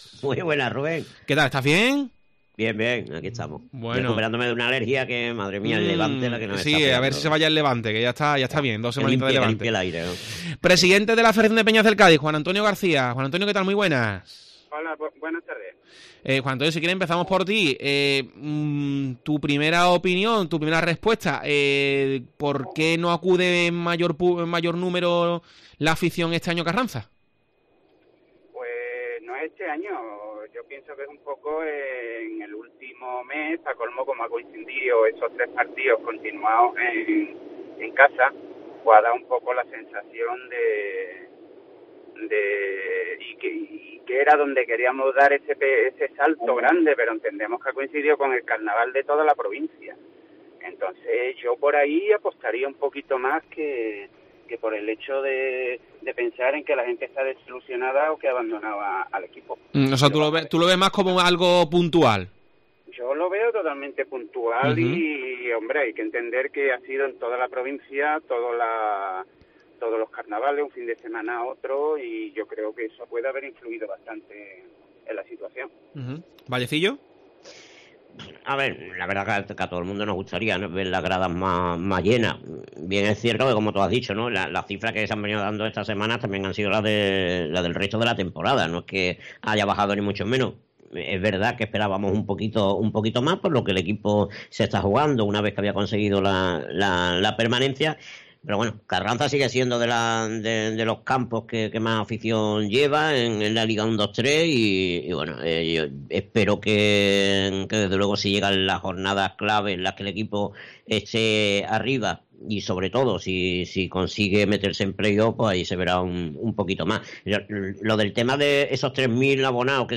Debate sobre la afición del Cádiz